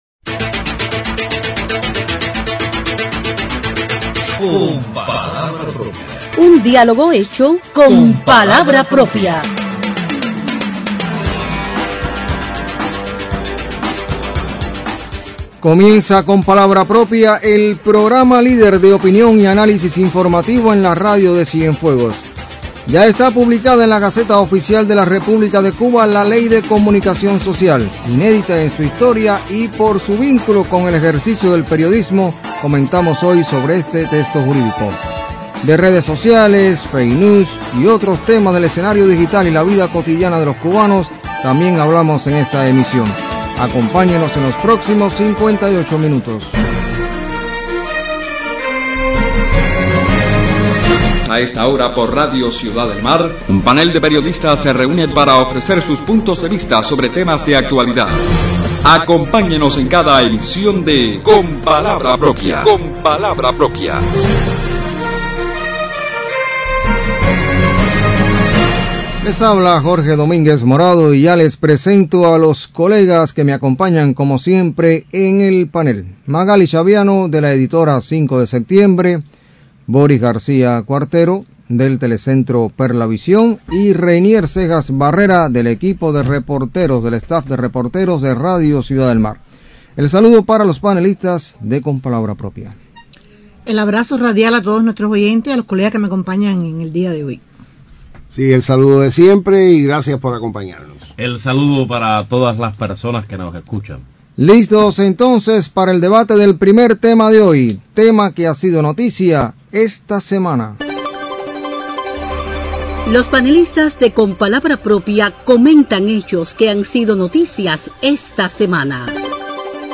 La Ley de Comunicación Social, recientemente publicada en la Gaceta Oficial de la República de Cuba, es tema de debate en Con palabra propia este 8 de junio.
De la feria de los sábados en la Calzada de Dolores y las fake News y campañas mediáticas también motiva los puntos de vista de los panelistas.